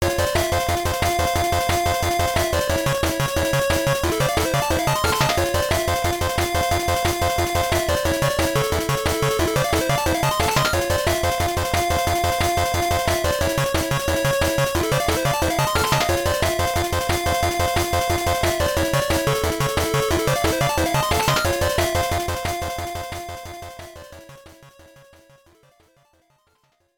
This file is an audio rip from a(n) Game Boy Color game.